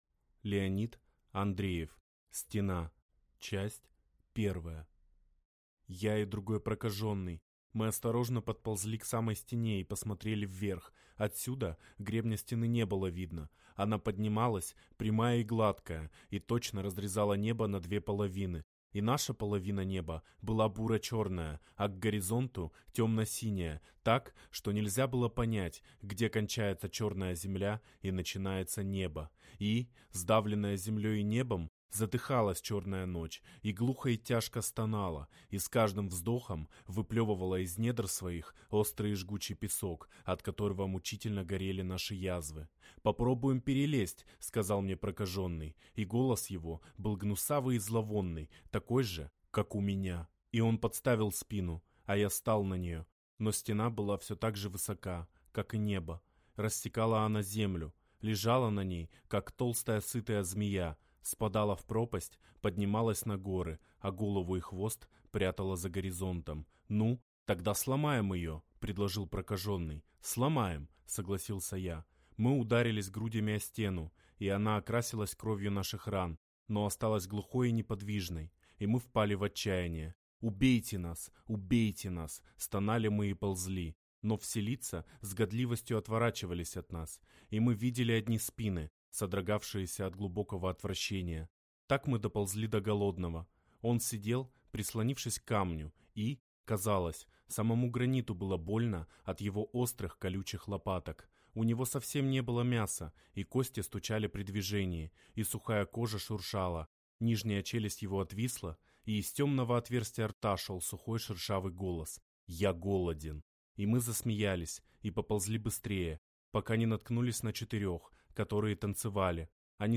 Аудиокнига Стена | Библиотека аудиокниг